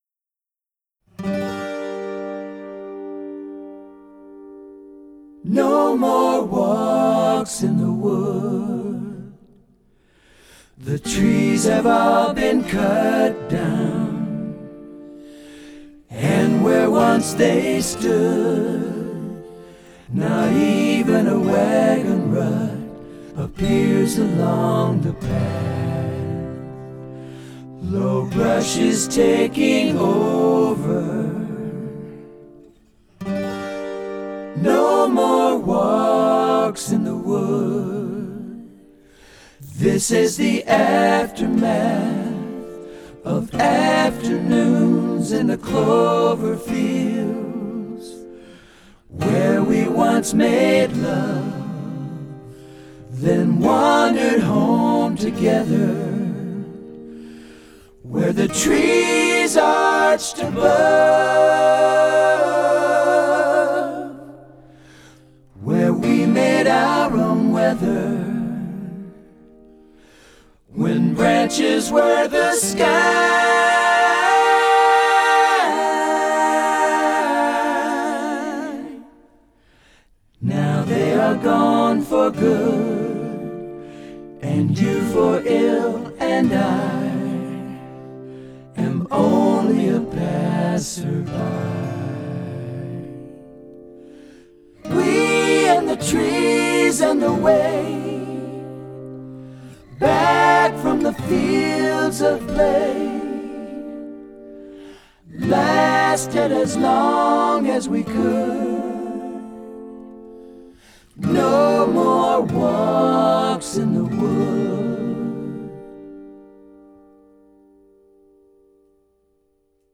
vienas bus cd ripas su EAC, kitas - vinyl ripas:
> Головка звукоснимателя: Lyra Skala